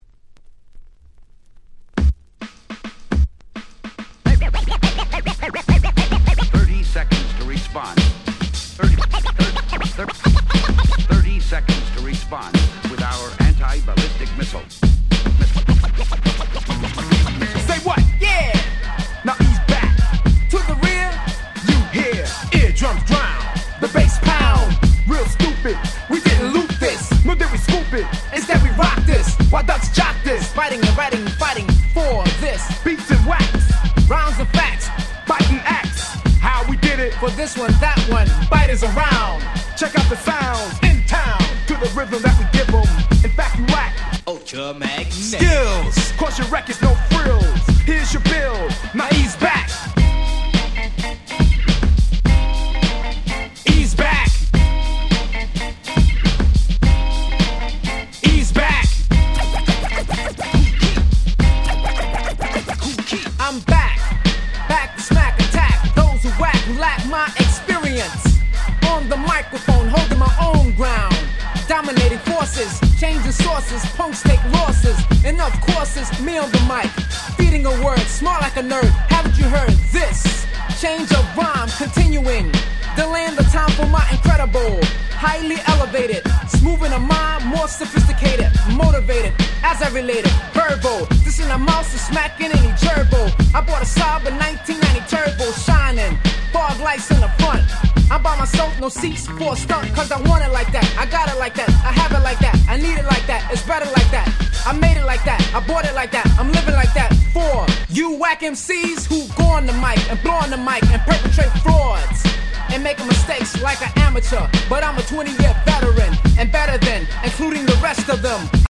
88' Hip Hop Super Classics !!
80's Hip Hopド定番！！
(Vocal)